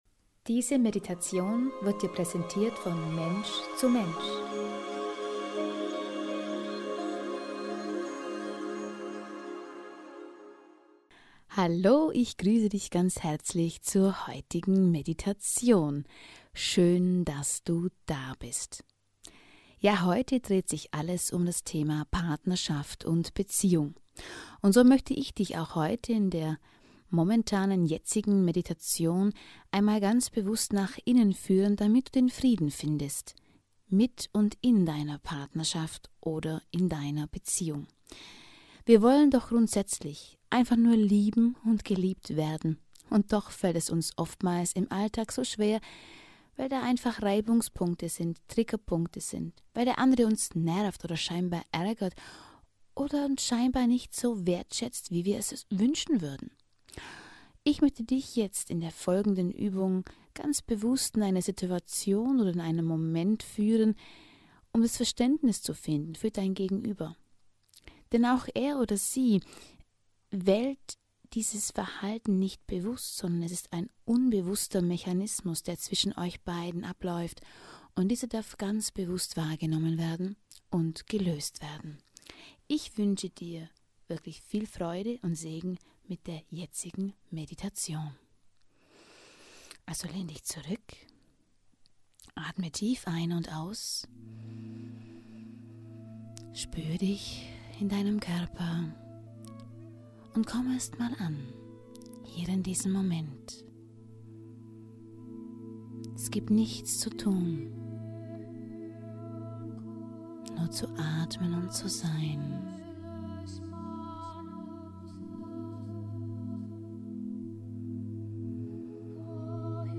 Damit deine Partnerschaft genau solch eine Oase der inneren Einkehr wird, laden wir dich ein, der heutigen Meditation zu lauschen.